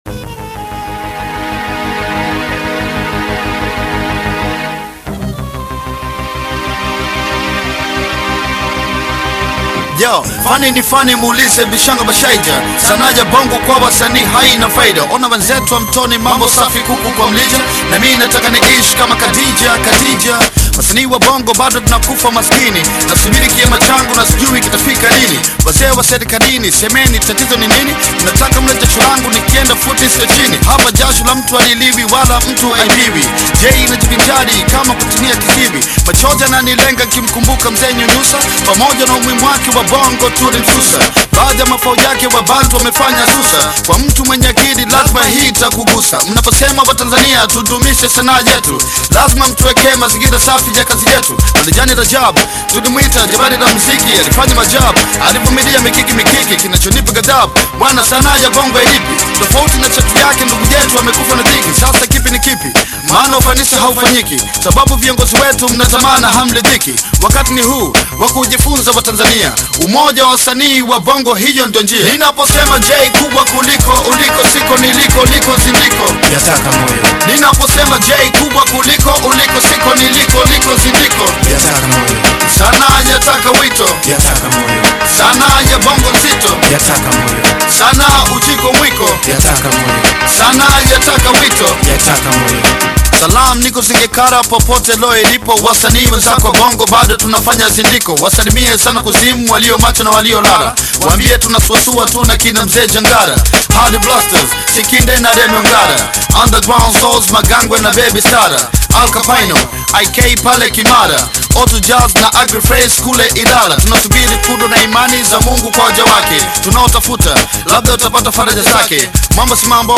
Bongo Fleva Kitambo